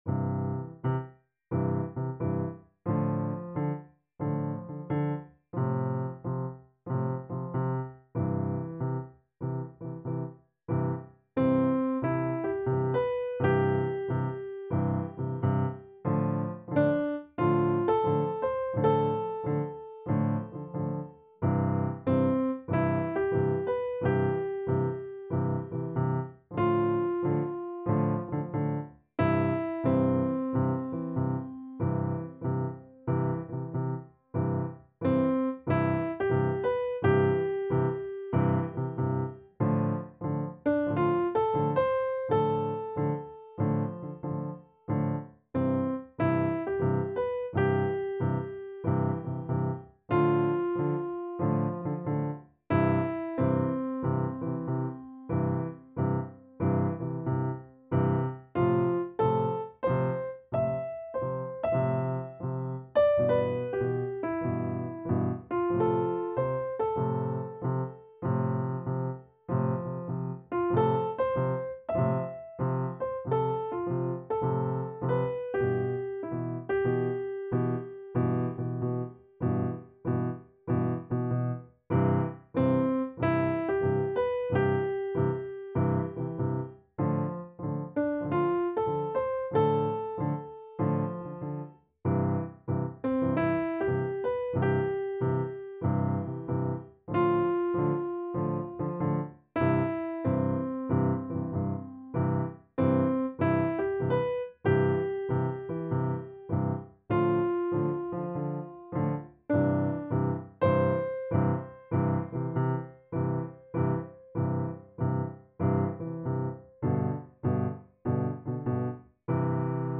6 instrumental